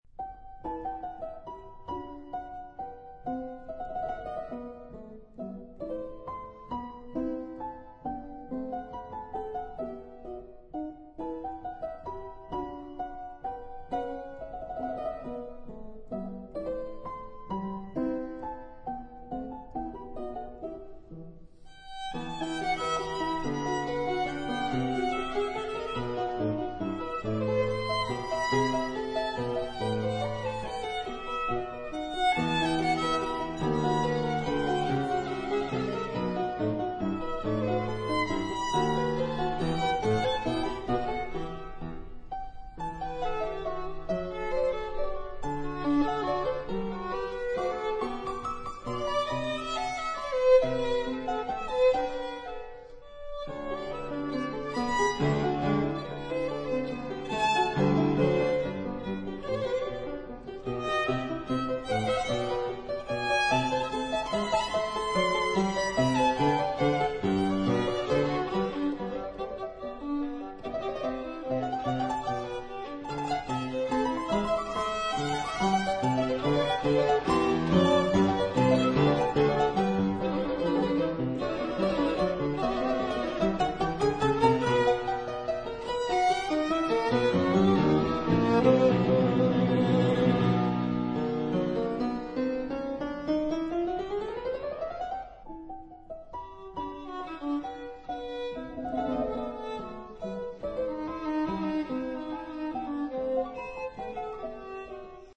帶有某種流動的、外顯的情緒，更有年輕的味道。
琴音的熱情鮮明。
卻是相當古典風格。